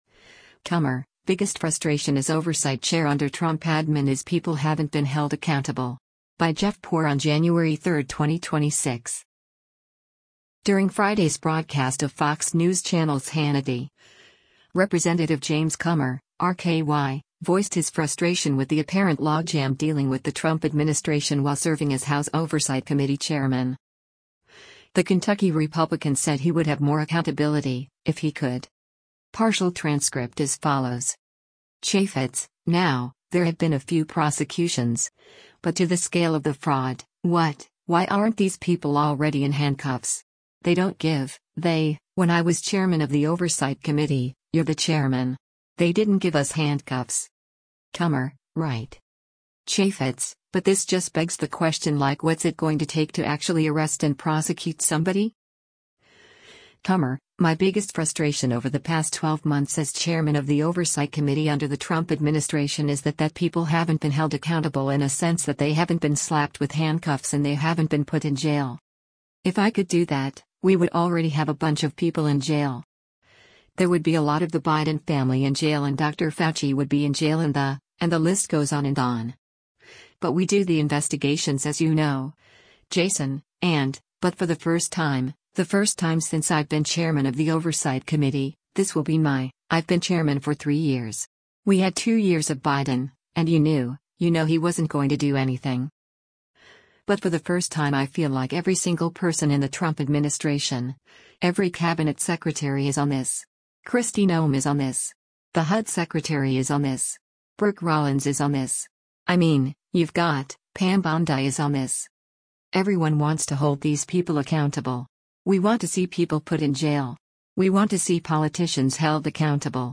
During Friday’s broadcast of Fox News Channel’s “Hannity,” Rep. James Comer (R-KY) voiced his frustration with the apparent logjam dealing with the Trump administration while serving as House Oversight Committee chairman.